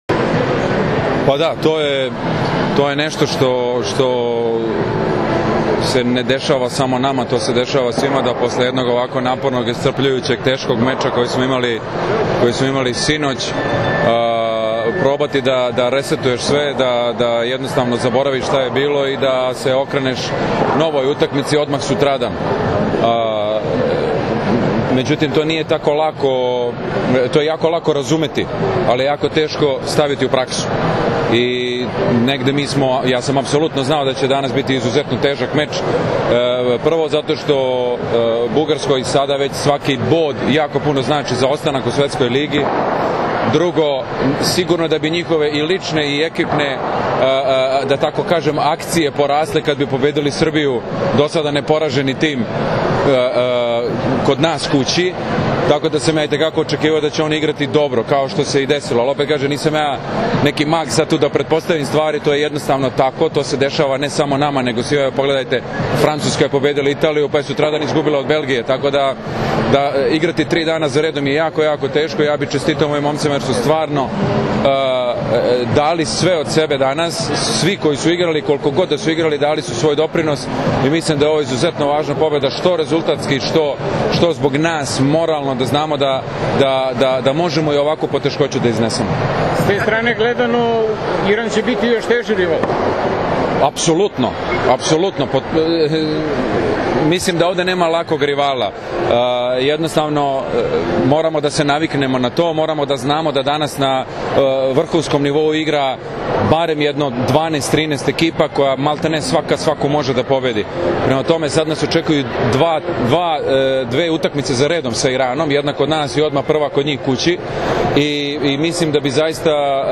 IZJAVA NIKOLE GRBIĆA